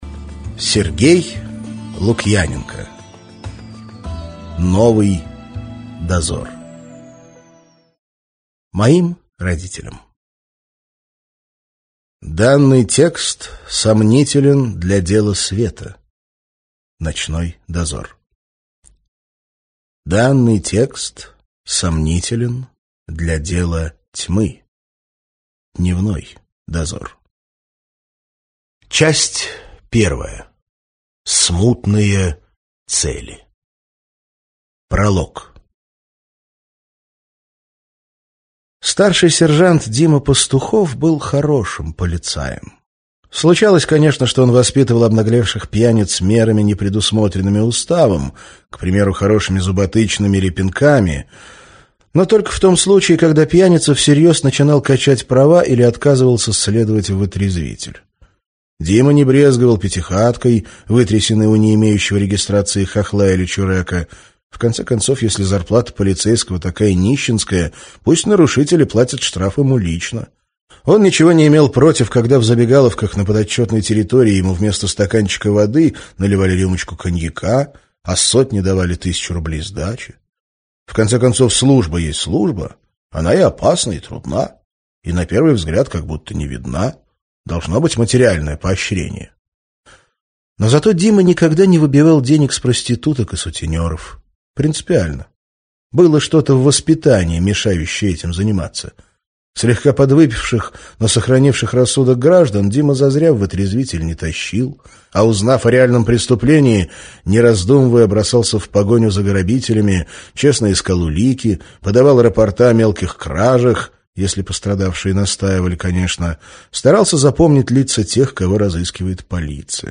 Аудиокнига Новый Дозор - купить, скачать и слушать онлайн | КнигоПоиск